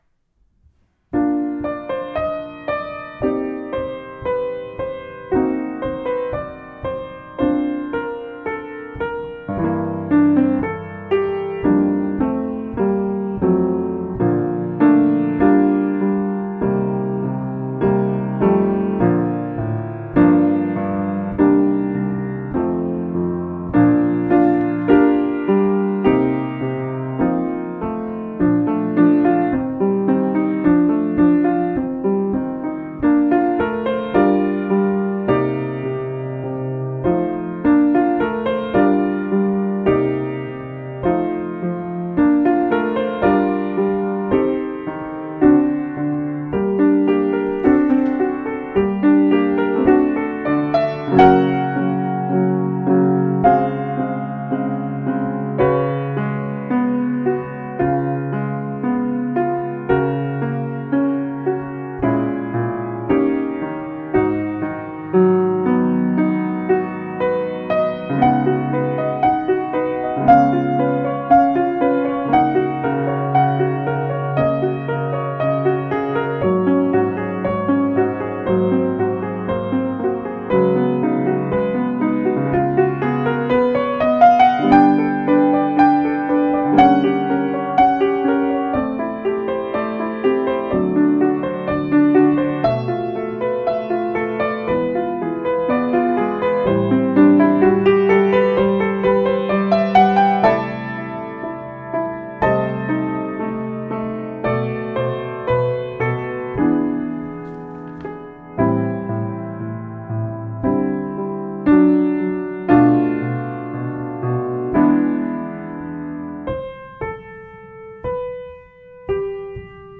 A-Christmas-Wish-acc.mp3